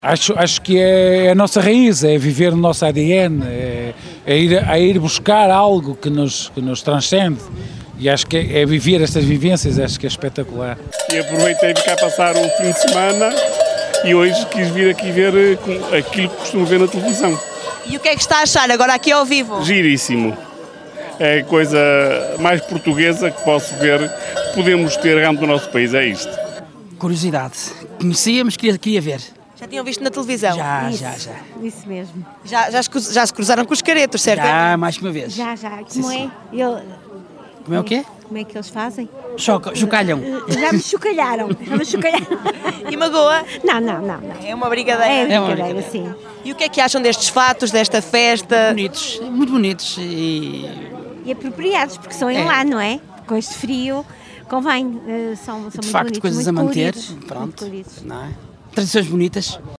vox-pop-entrudo.mp3